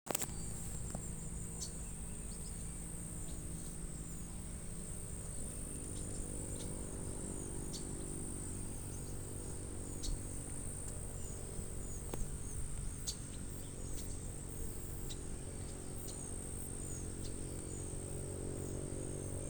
Chestnut-capped Blackbird (Chrysomus ruficapillus)
Country: Argentina
Detailed location: Dique Río Hondo
Condition: Wild
Certainty: Photographed, Recorded vocal